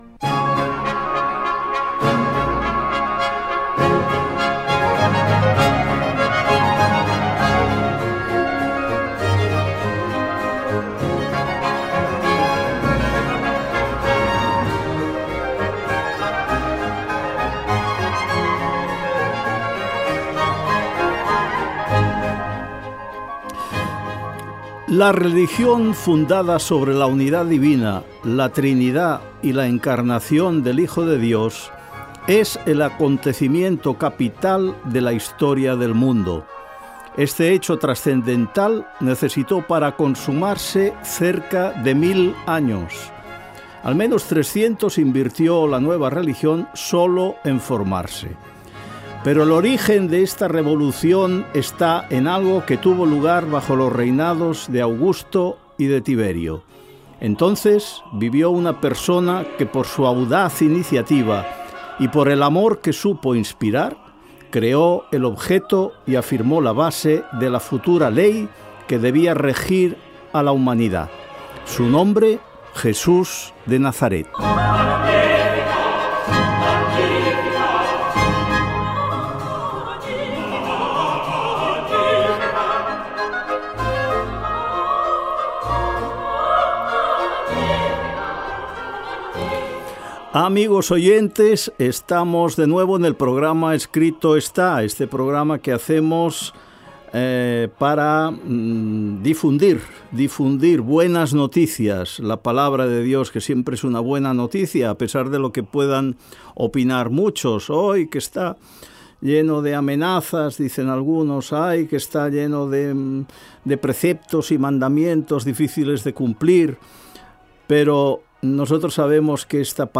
Religiosa